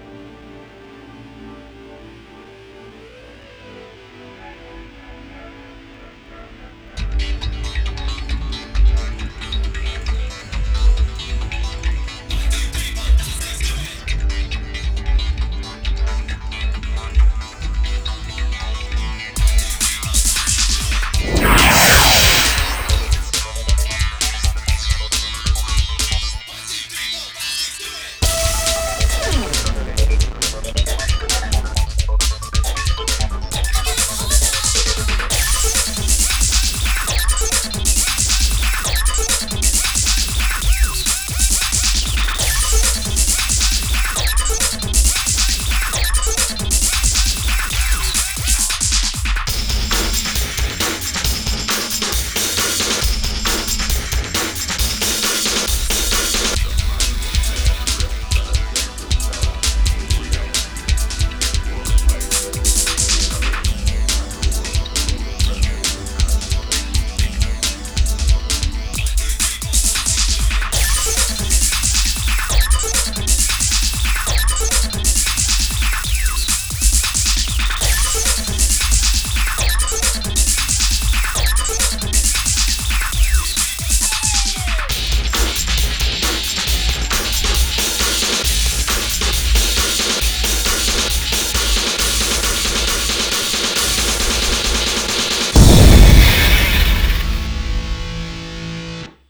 BPM136
Comments[BIG BEAT]